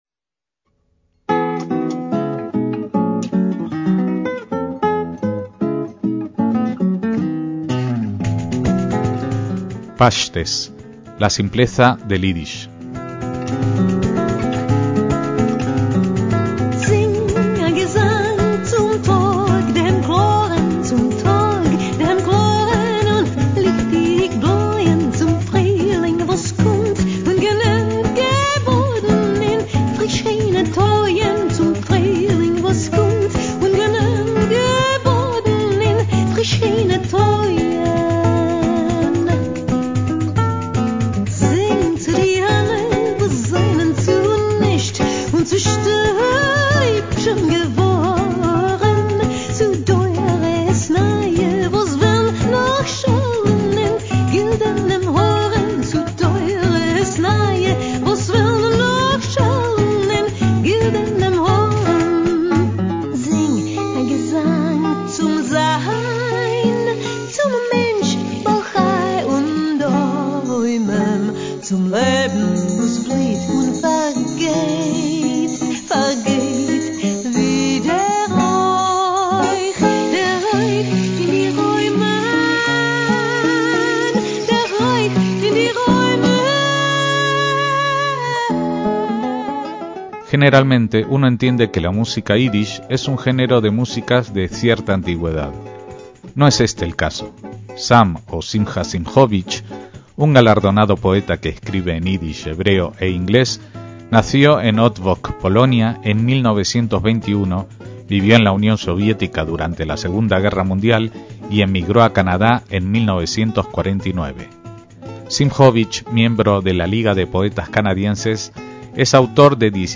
MÚSICA ÍDISH